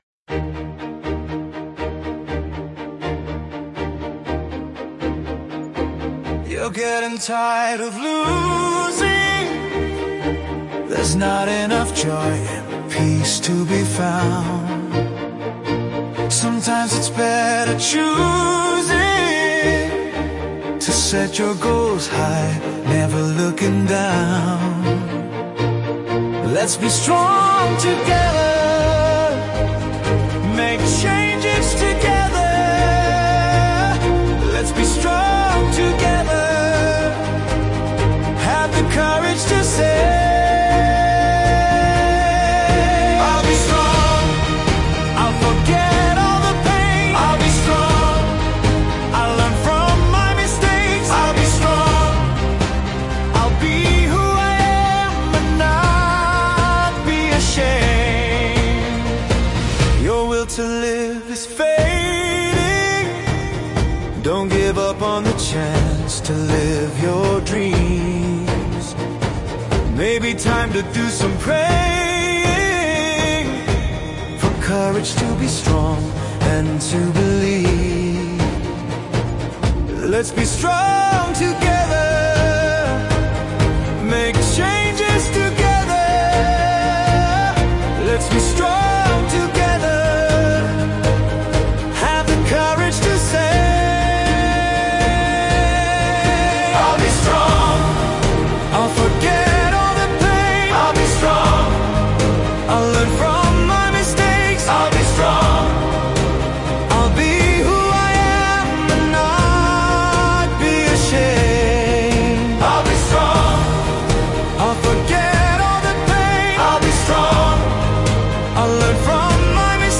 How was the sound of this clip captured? Revised with music and vocals